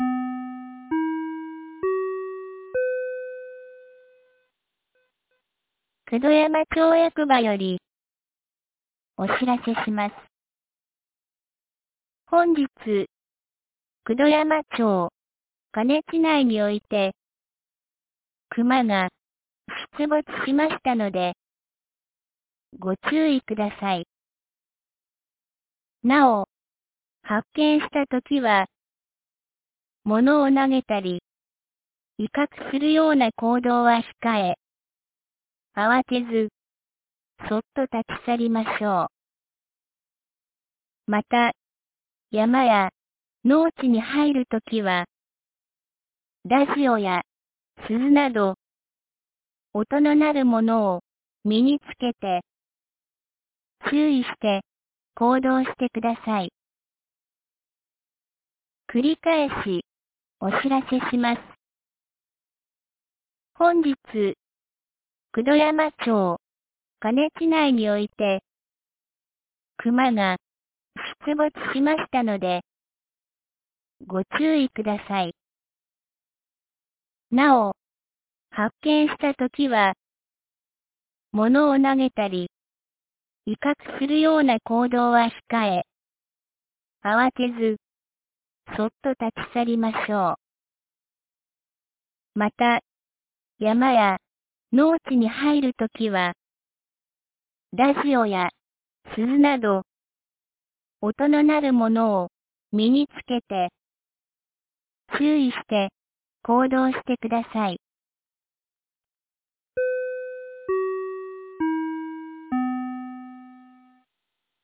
2024年12月17日 17時07分に、九度山町より下古沢地区、椎出地区、中古沢地区、上古沢地区、笠木地区、河根地区、丹生川地区へ放送がありました。